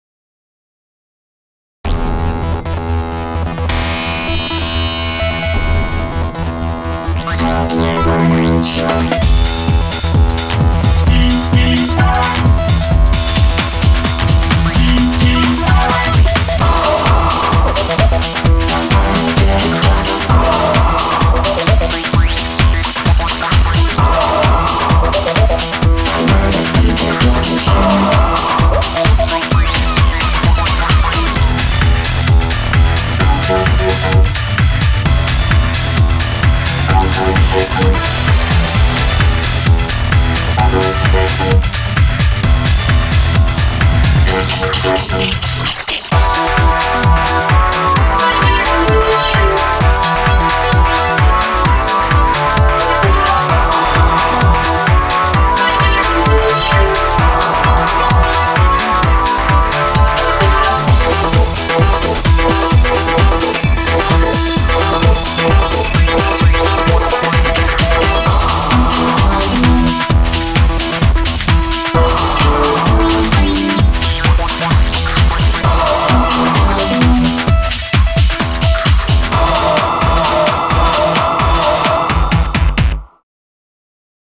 BPM130
MP3 QualityMusic Cut